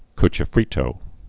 (kchĭ-frētō)